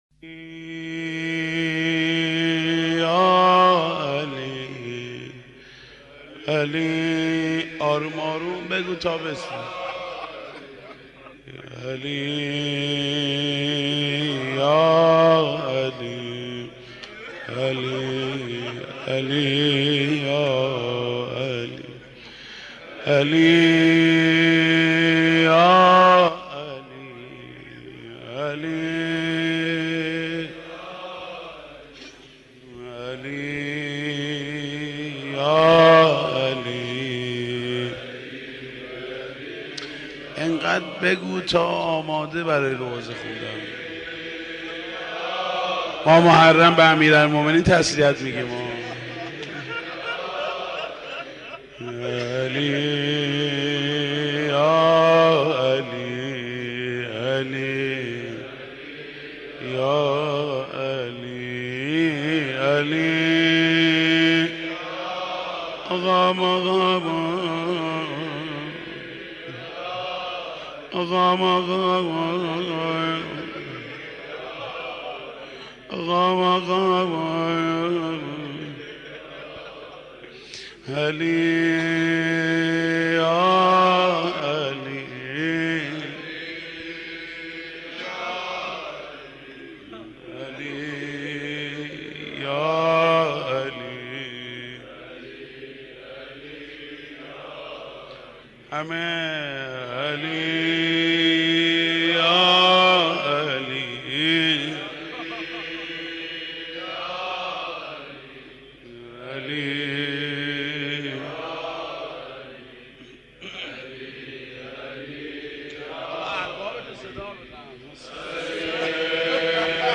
مداح
مناسبت : شب ششم محرم
مداح : محمود کریمی